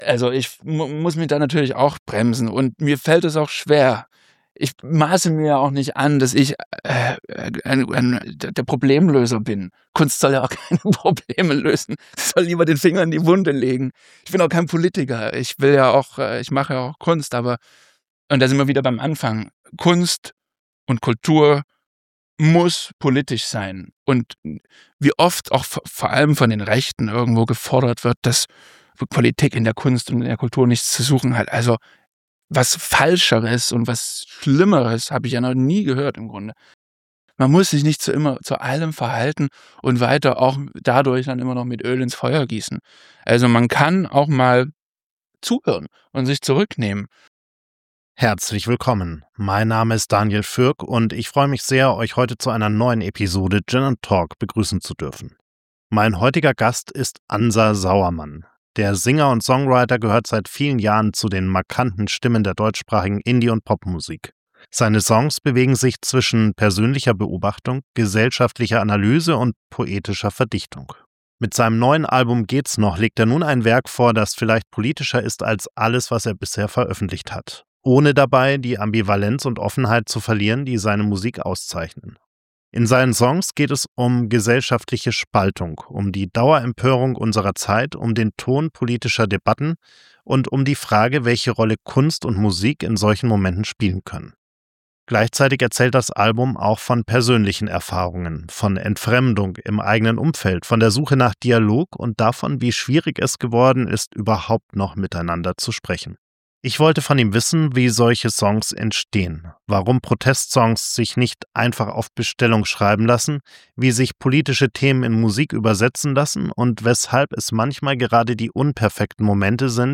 Ein Gespräch über Musik, Inspiration und darüber, wie es sich anfühlt, seit nun einem Jahr nicht mehr vor Publikum auftreten zu können.